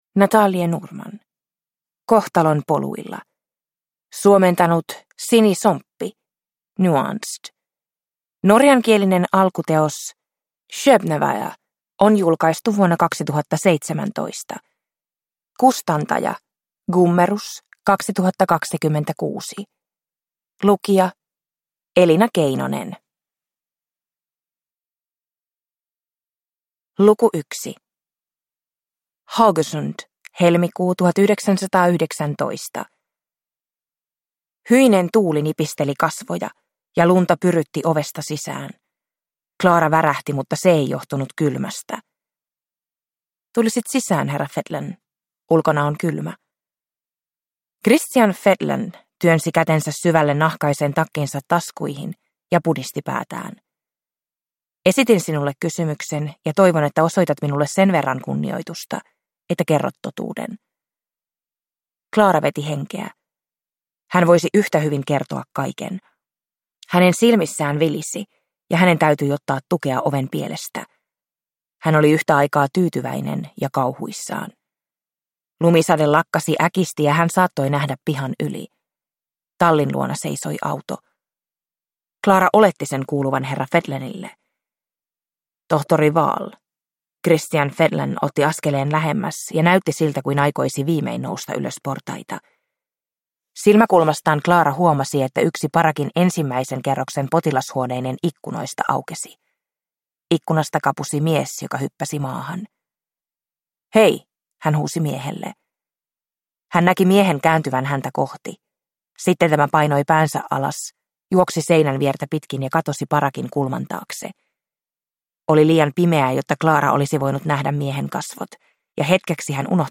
Kohtalon poluilla – Ljudbok